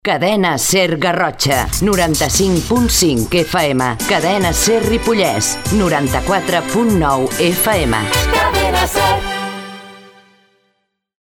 6e1b82539fe05d231b335ce7ad40164a9c4b60c7.mp3 Títol Cadena SER Garrotxa Emissora Cadena SER Garrotxa Cadena SER Titularitat Privada estatal Descripció Identificació de Cadena SER Garrotxa i Cadena SER Ripollès.